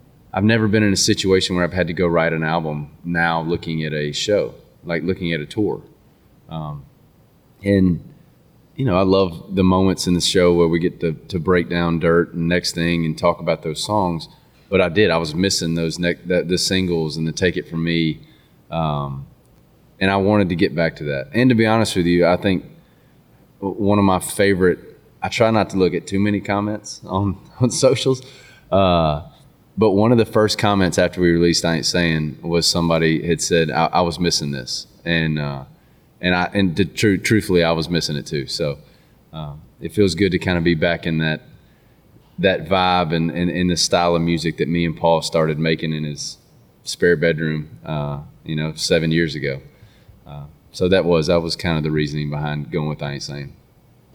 In a recent interview at his number 1 celebration for “Tuscon Too Late,” Jordan Davis talks about the reasoning behind choosing “I Ain’t Sayin'” as the next single to Country Radio.